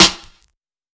Dilla Snare 29.wav